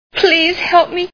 Gentlemen Prefer Blondes Movie Sound Bites